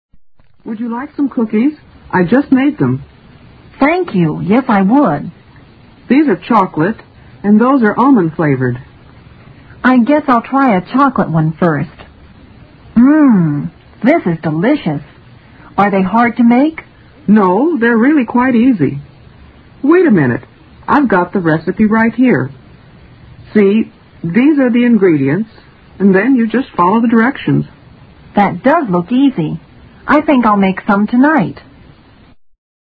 Dialoge 1